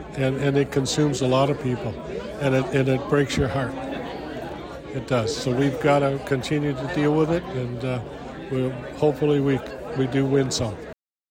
Quinte West Mayor Jim Harrison gave his State of the City Address to the Chamber of Commerce on Friday touching on some of the successes of 2022 and looked ahead to what’s to come this year.
The event was held at the Ramada in Trenton.